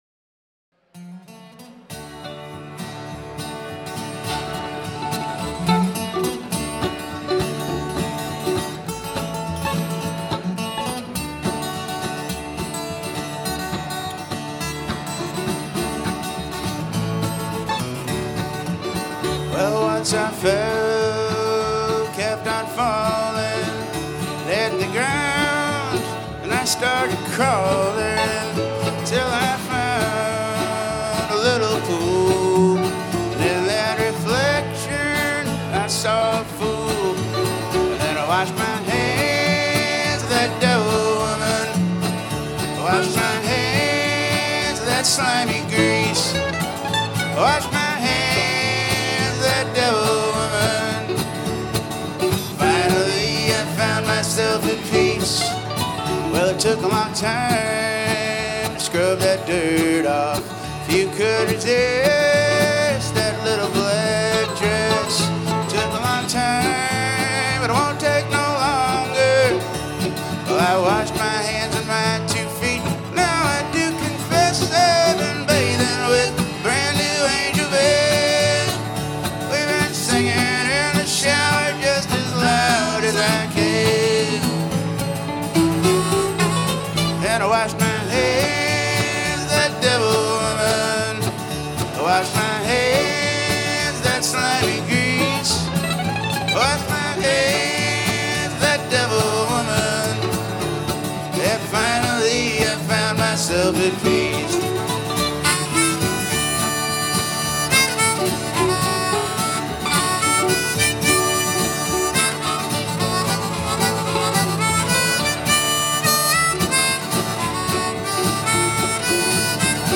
Wallenpaupack Brewing Company Hawley, PA
Guitar/Vocals
Mandolin/Electric Guitar/Vocals
Bass/Vocals
Support Live Music!